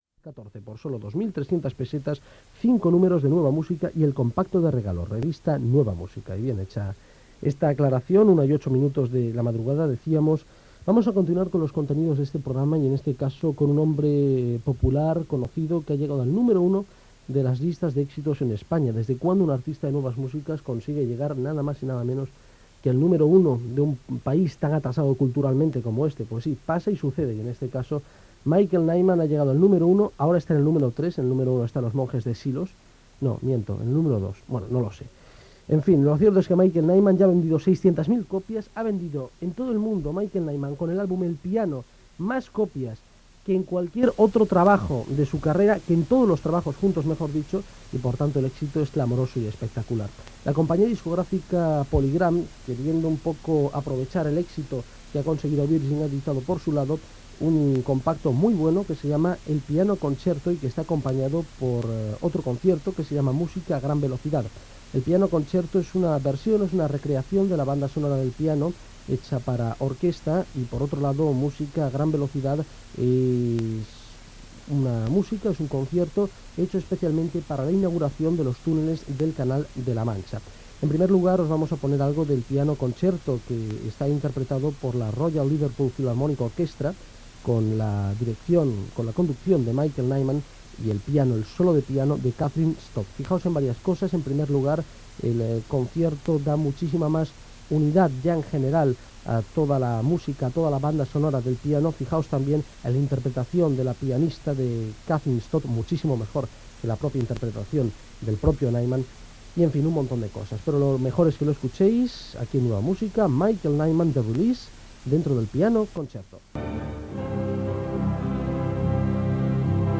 Publicitat, hora, presentació d'un tema musical, informació de la gira de Michael Nyman, tema musical, telèfon de participació, indicatiu del programa, hora, identificació de l'emissora i novetat musical Gènere radiofònic Musical